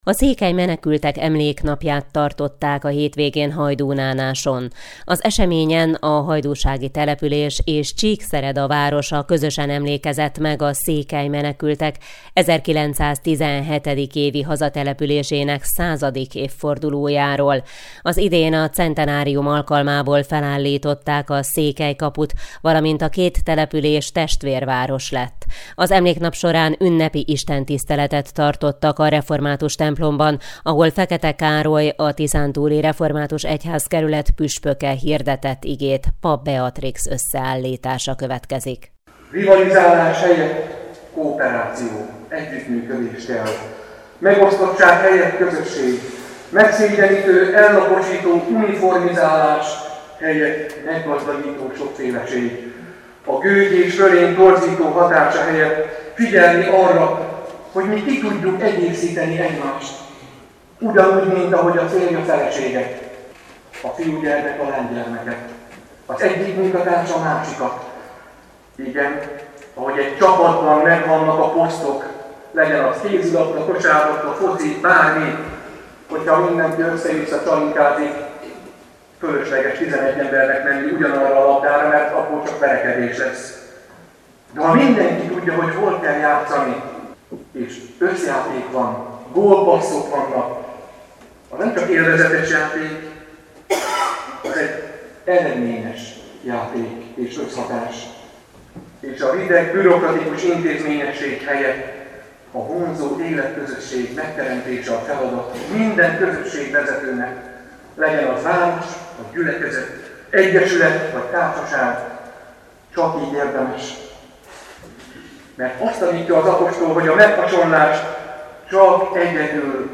Az ünnepi istentiszteleten Főtiszteletű Dr. Fekete Károly tiszántúli református püspök úr Krisztus testének egységének képe alapján (1Kor 12,12; 21kk) hirdette Isten közösségre nevelő, bátorító aktuális üzenetét.